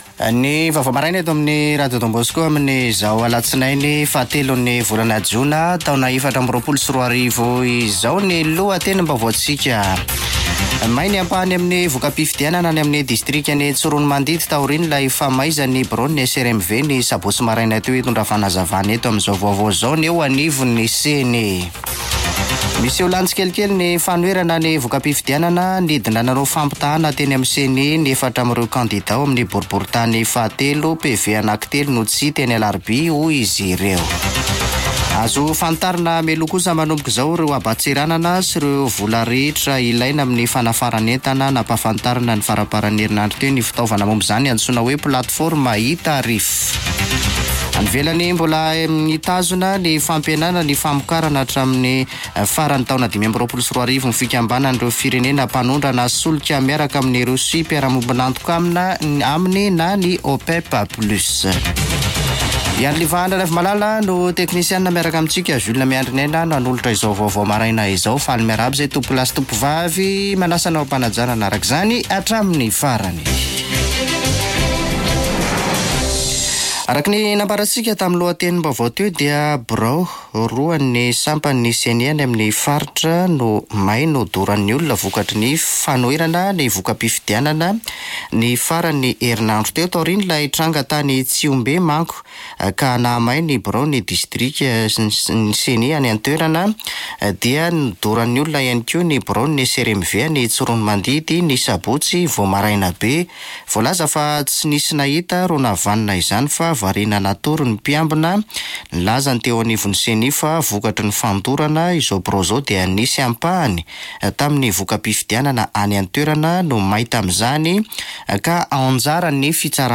[Vaovao maraina] Alatsinainy 03 jona 2024